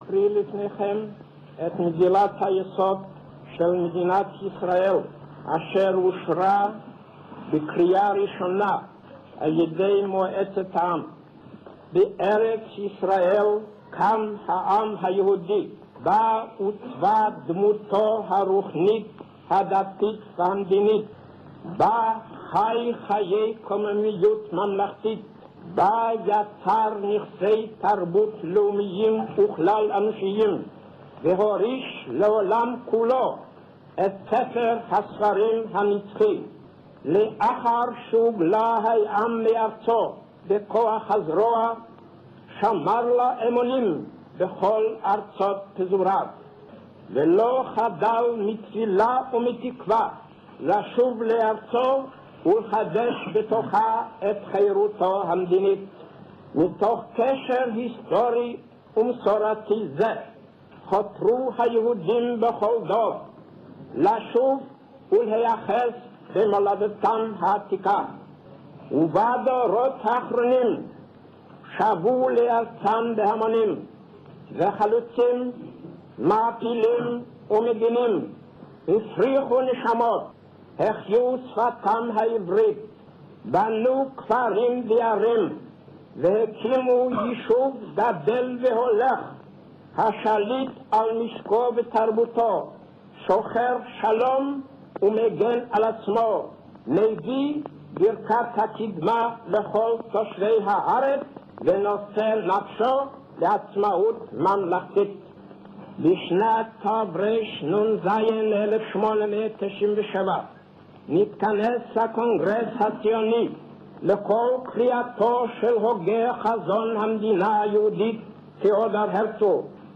Listen to David Ben Gurion's Historical Declaration of Independence on 14 May 1948 in Tel Aviv